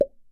balloon bubble menu pop sound effect free sound royalty free Sound Effects